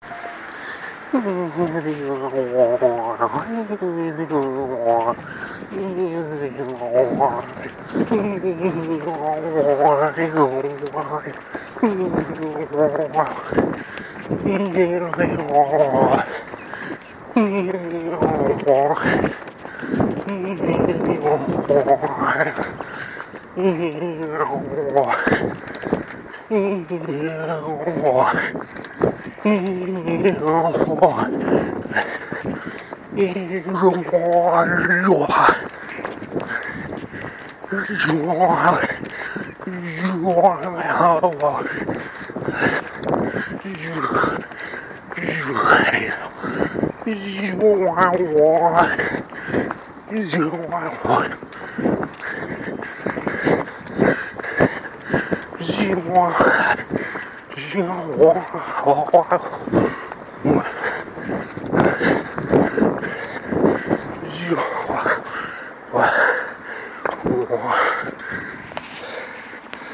Vocal improv in response to clear sky while running